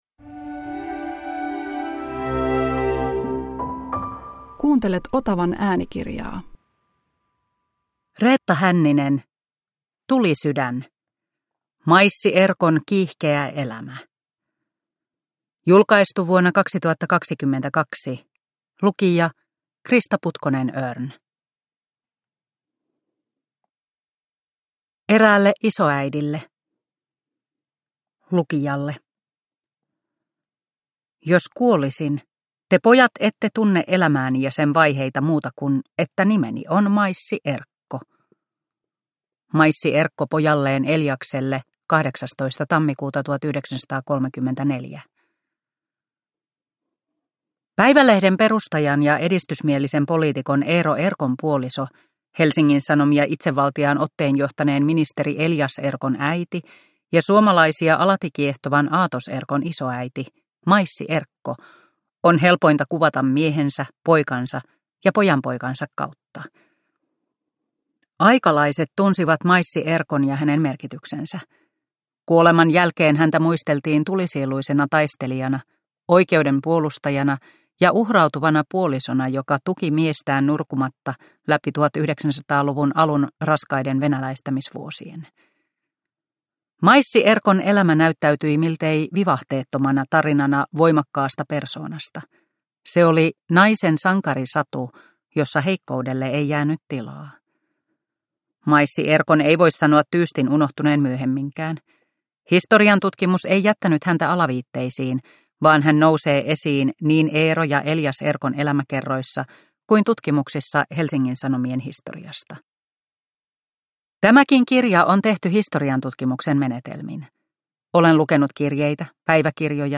Tulisydän – Ljudbok – Laddas ner